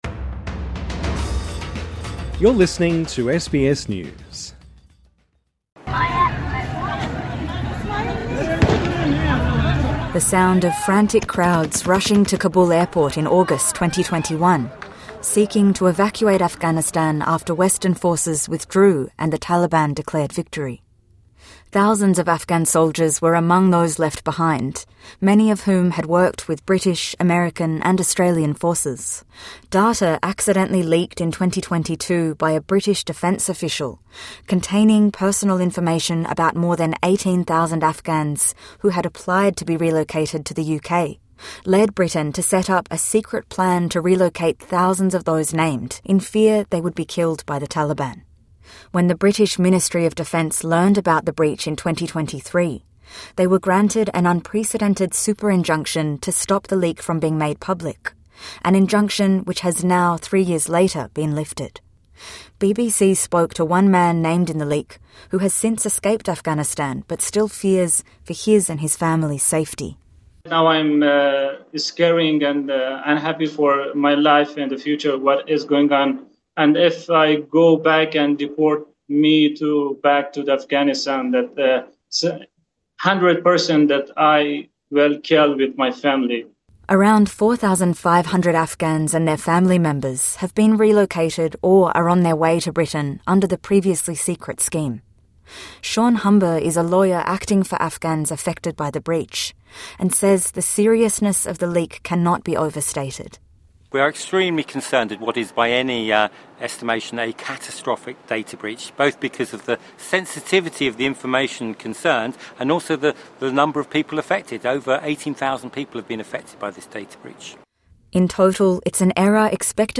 TRANSCRIPT The sound of frantic crowds rushing to Kabul airport in August 2021, seeking to evacuate Afghanistan after Western forces withdrew, and the Taliban declared victory.